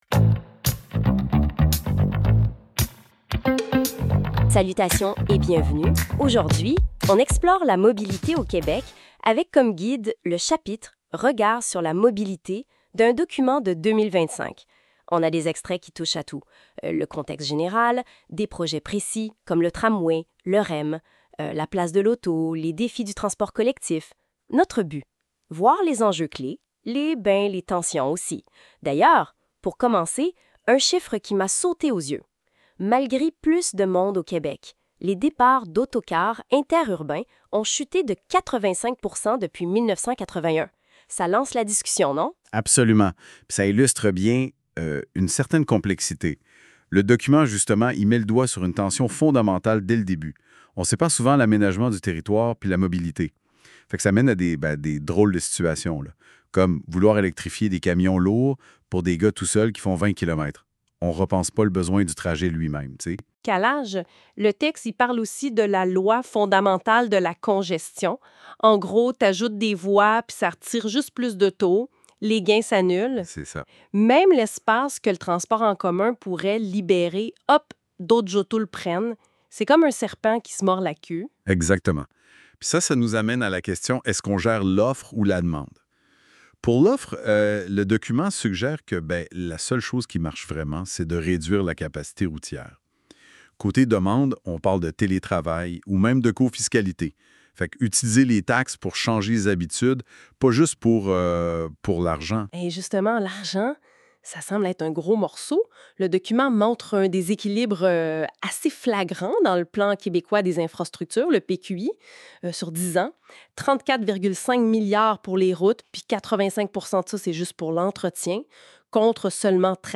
Ce podcast est généré par intelligence articifielle